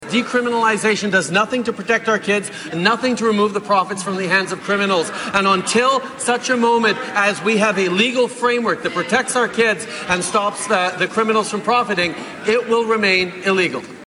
Prime Minister Justin Trudeau responded, saying that legalization is about protecting children, and keeping money out of organized crime.